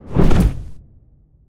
whoosh_magic_spell_02.wav